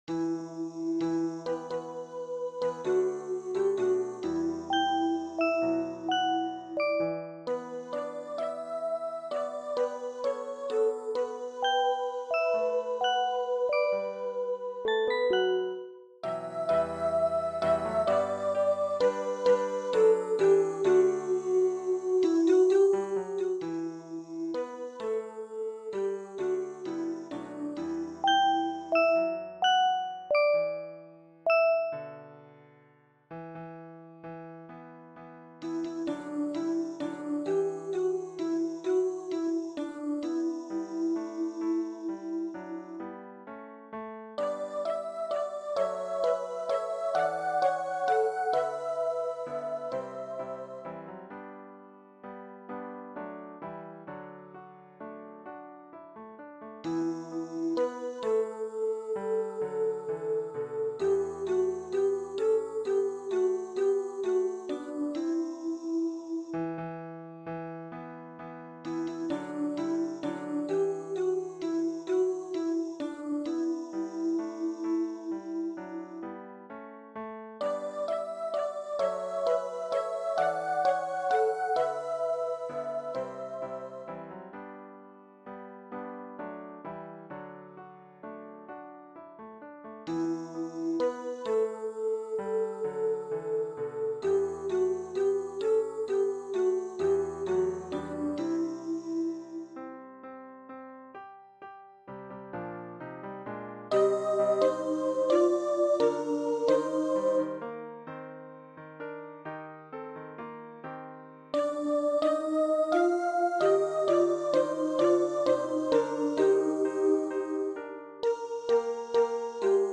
för 4-stämmig blandad kör